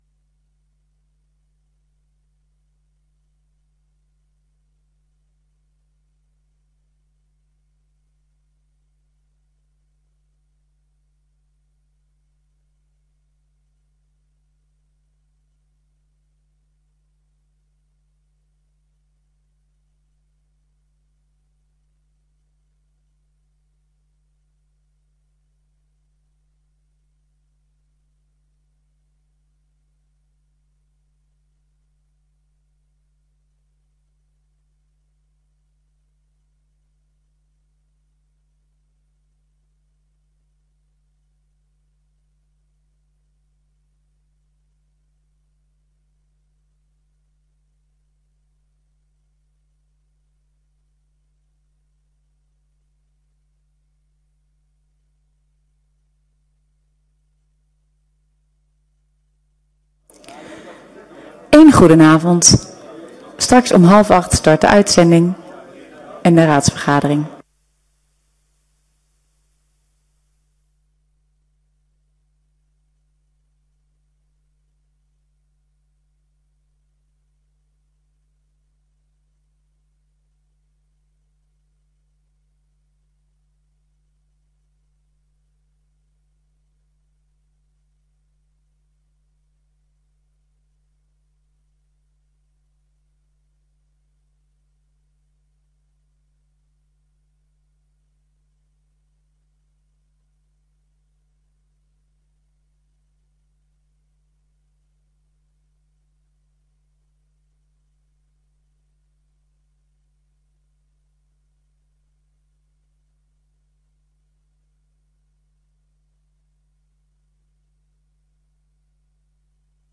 Gemeenteraad 20 juni 2022 19:30:00, Gemeente Dalfsen
Download de volledige audio van deze vergadering